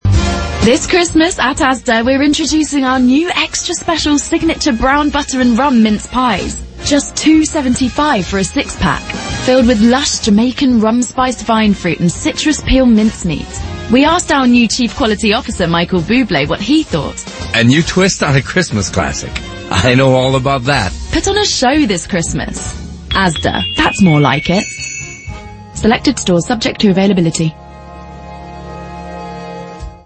While the radio campaign delivers against its tactical objectives, its clever use of swing music helps to secure long-term brand effects by bringing to mind the “King of Christmas” (Bublé) and the TV ad. This gets reinforced by Bublé’s seal of approval at the end, sprinkling a little extra seasonal magic.